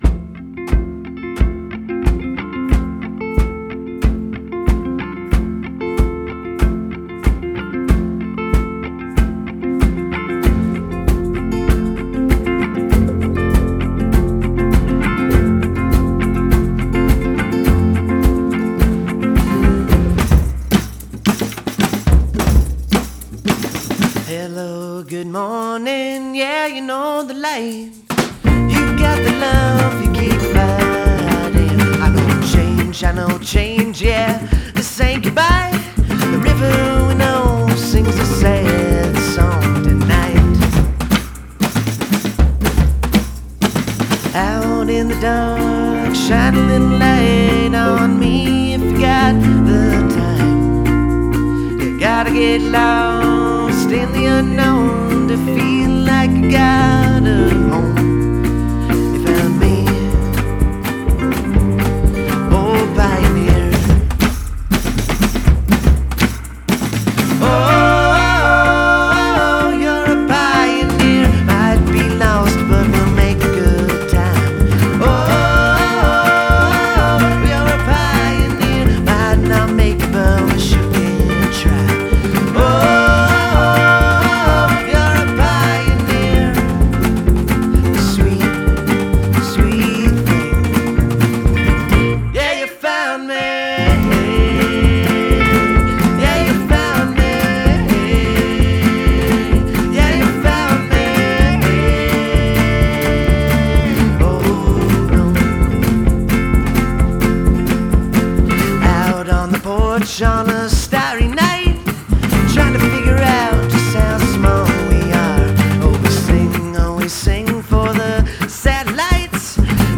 la musique "americana"